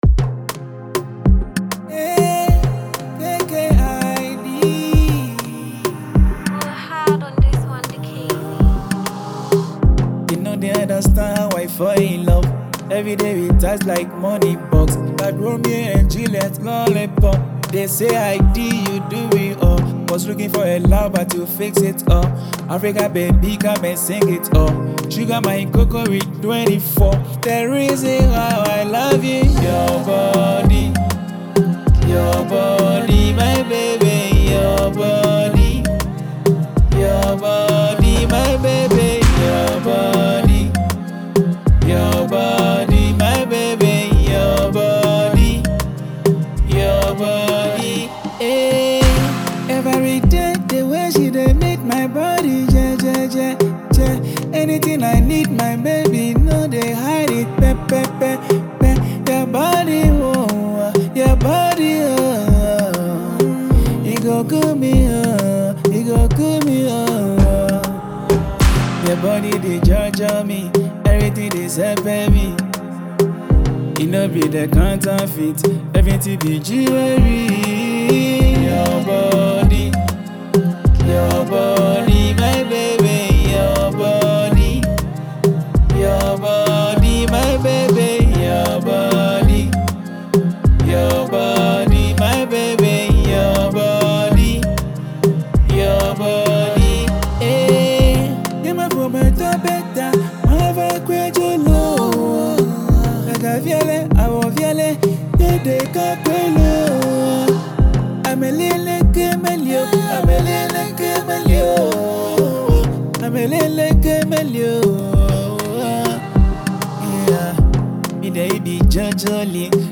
love song
With his soulful vocals and heartfelt lyrics